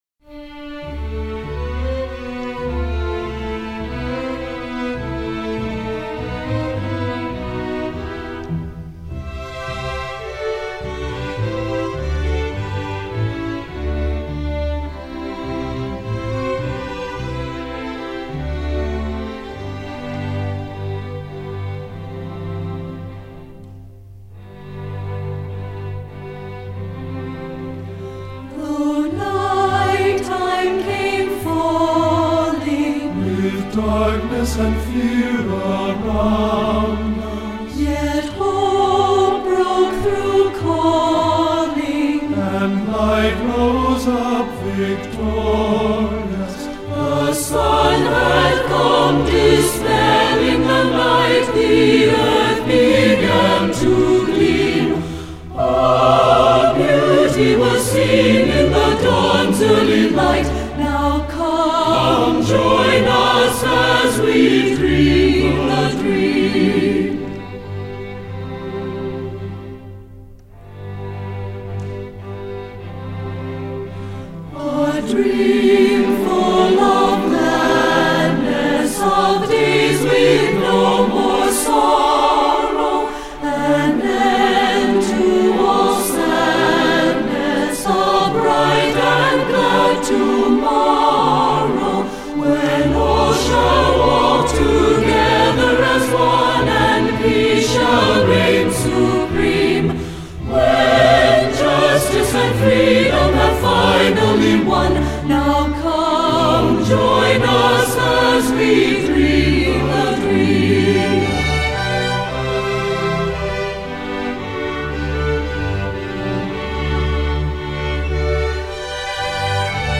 Voicing: 3-Part Mixed